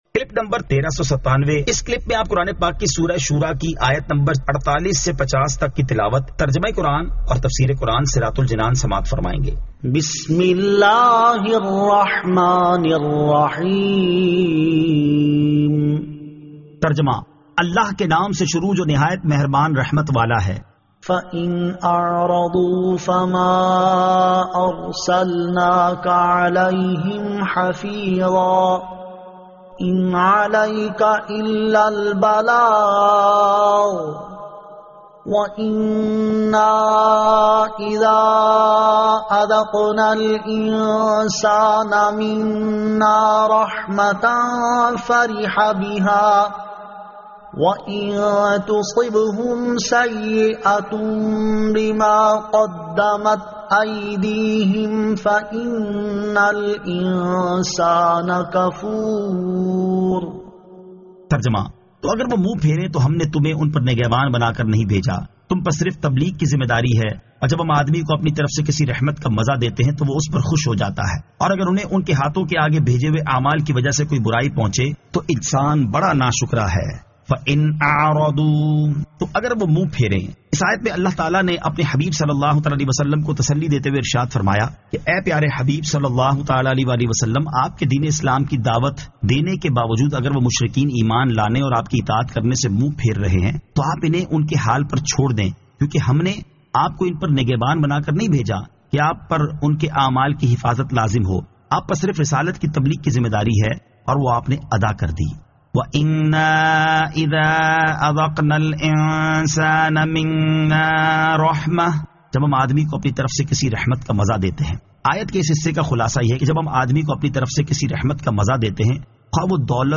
Surah Ash-Shuraa 48 To 50 Tilawat , Tarjama , Tafseer